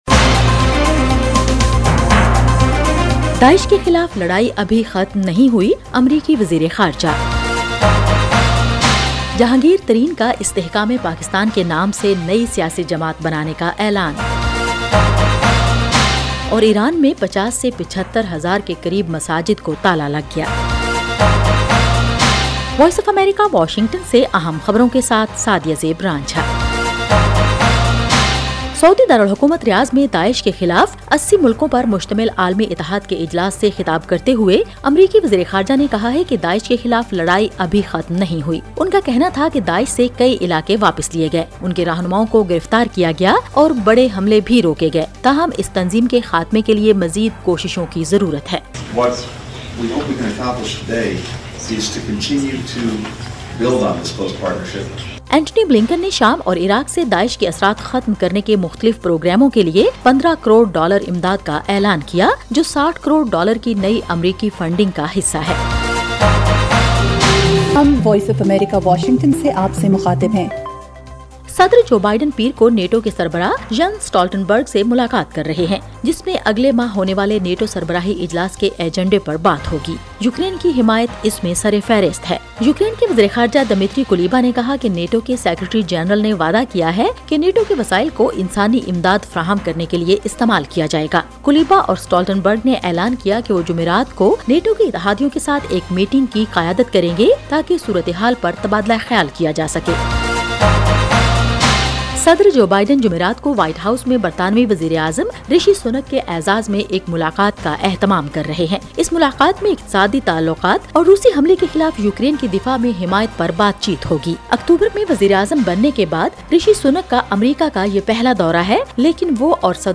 ایف ایم ریڈیو نیوز بلیٹن: رات 10 بجے